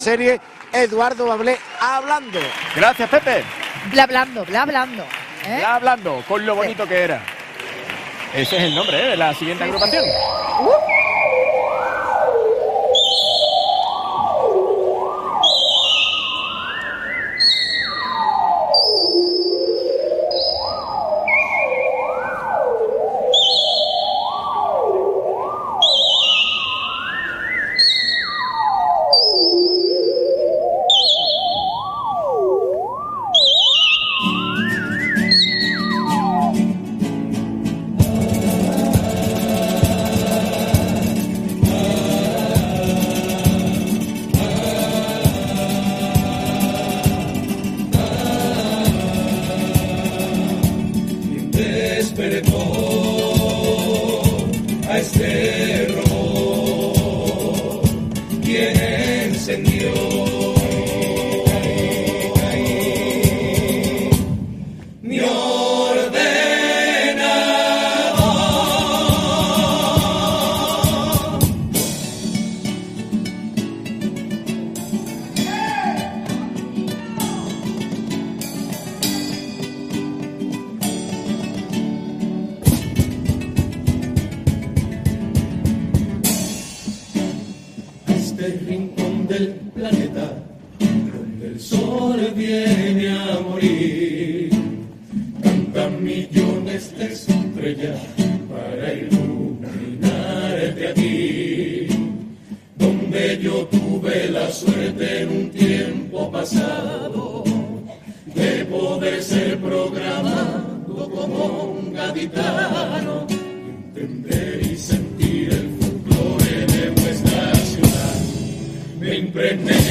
Escucha y descarga el audio de Con lo bonito que era de Preliminares del Concurso Oficial de Agrupaciones del Carnaval de Cádiz (COAC) 2024 en formato MP3 y de manera gratuita
Disfruta de la actuación de la Comparsa Con lo bonito que era en la preliminares del COAC 2024.